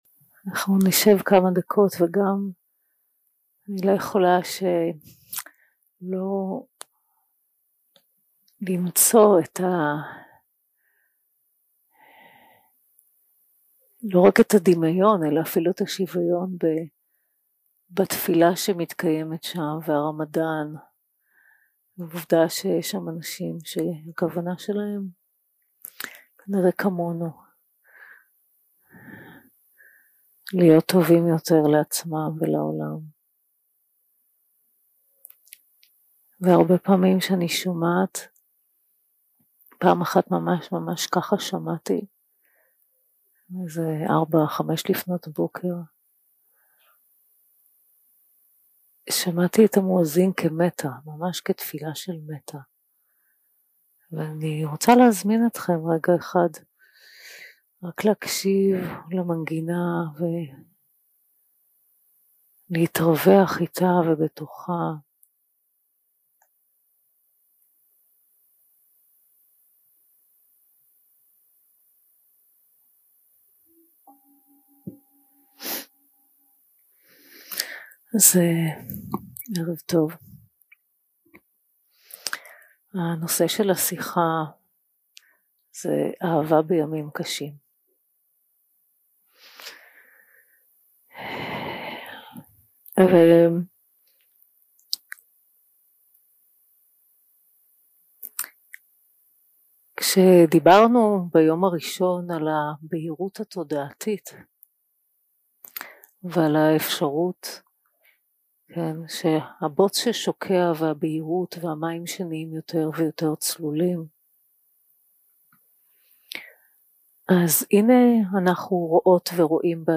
Dharma Talks שפת ההקלטה